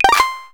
Achievement Unlocked and Leaderboard Submitted sound effects
sfx_leaderbord_submitted.wav